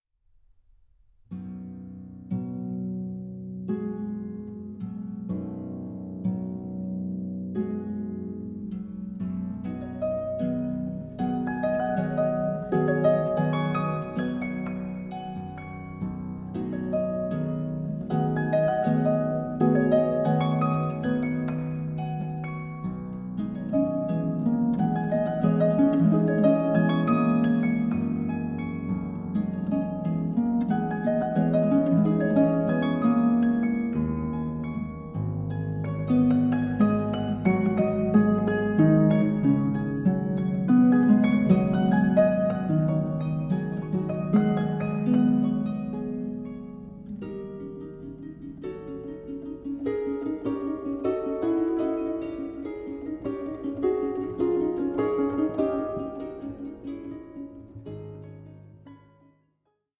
for harp